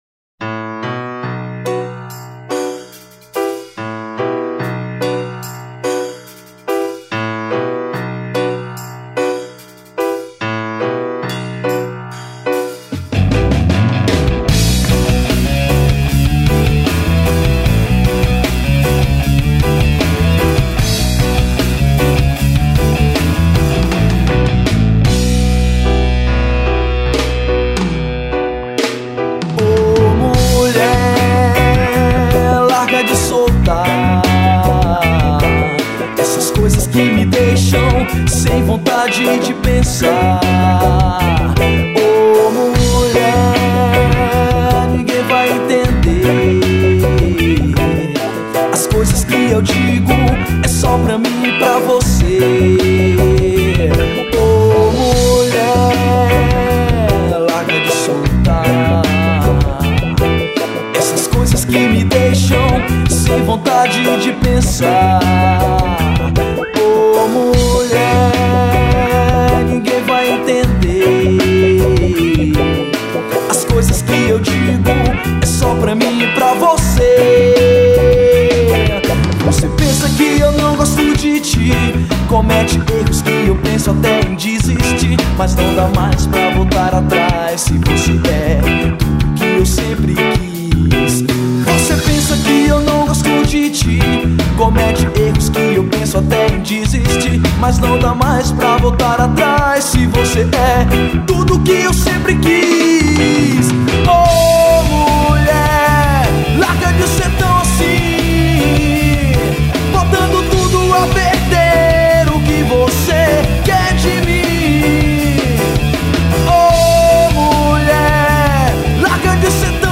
2772   04:03:00   Faixa: 6    Rock Nacional